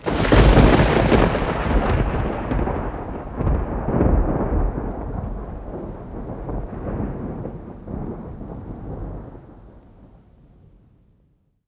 1 channel
CLAP1.mp3